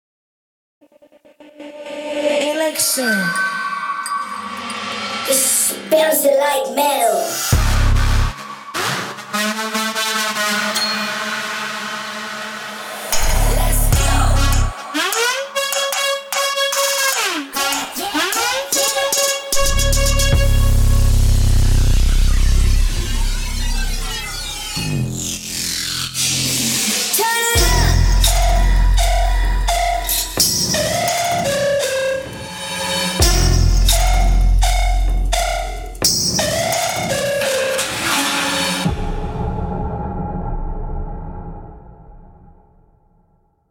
风格大师综合采样音色
音色试听
电音采样包